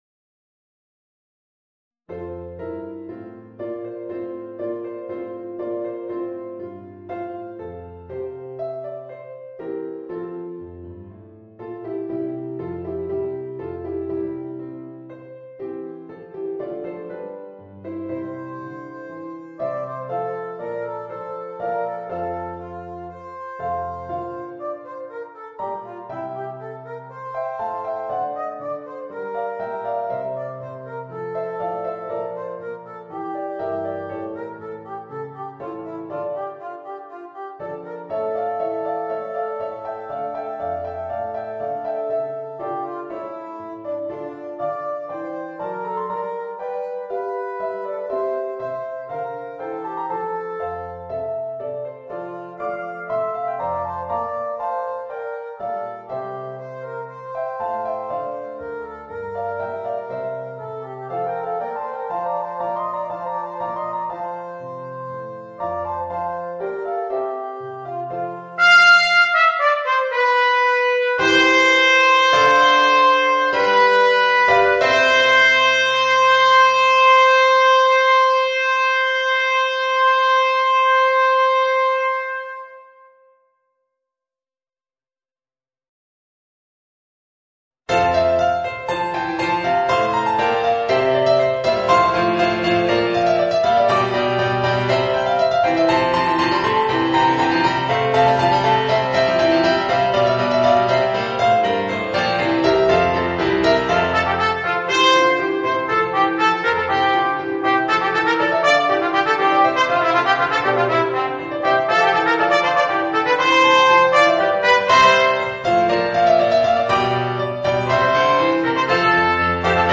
Voicing: Trumpet and Organ